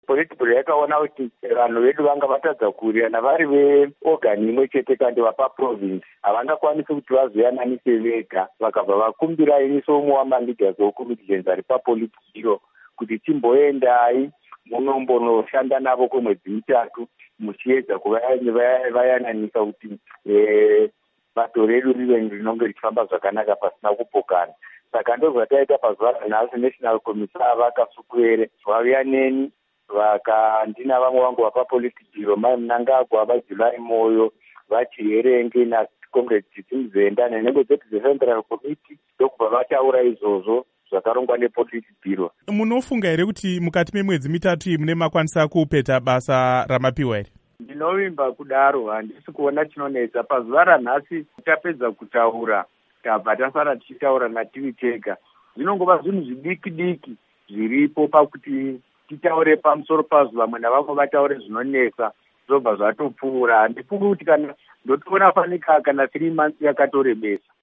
Hurukuro naVaIgnatious Chombo1